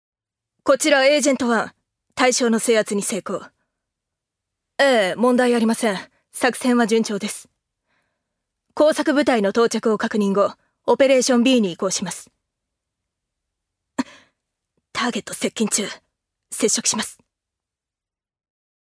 女性タレント
音声サンプル
セリフ３